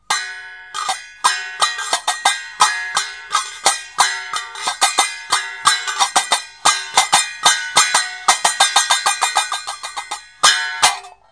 Chũm Chọe
4.2.NHẠC KHÍ TỰ THÂN VANG
m thanh Chũm Chọe to, vang, hơi chói tai.